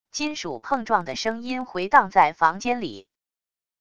金属碰撞的声音回荡在房间里wav音频